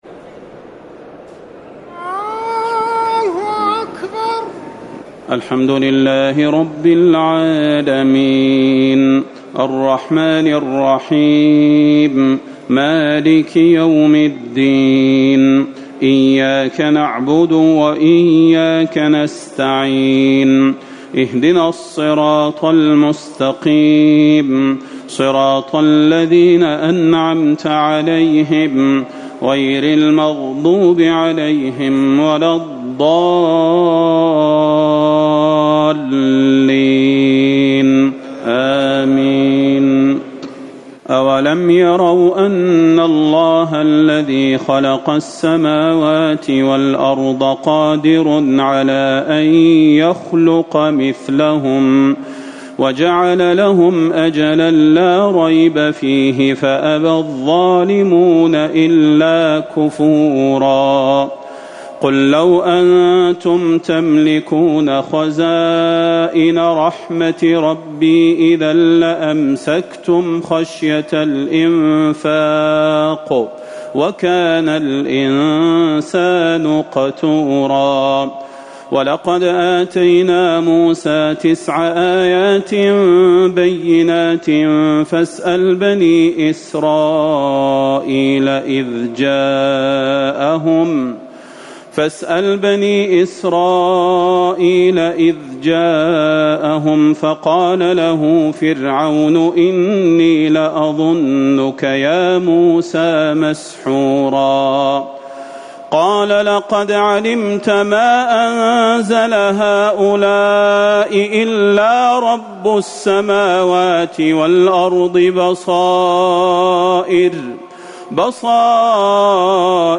ليلة ١٤ رمضان ١٤٤٠ سورة الاسراء ٩٩- الكهف ٥٩ > تراويح الحرم النبوي عام 1440 🕌 > التراويح - تلاوات الحرمين